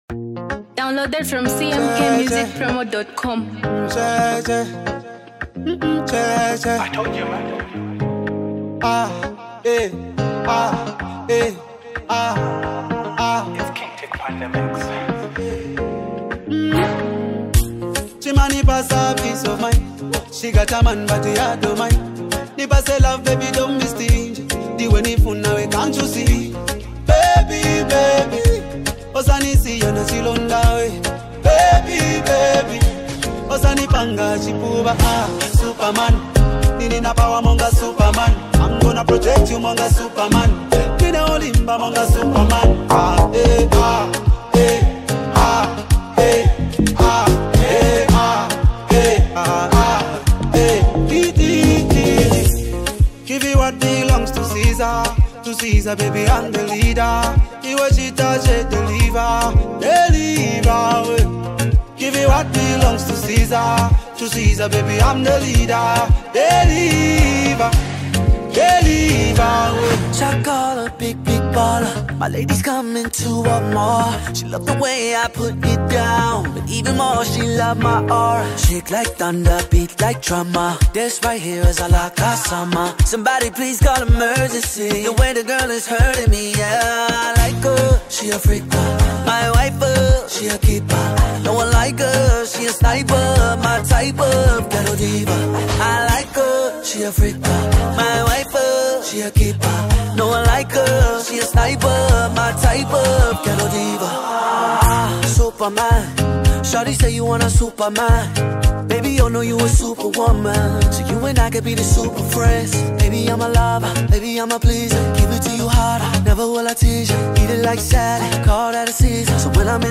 Get ready to groove to the ultimate party anthem!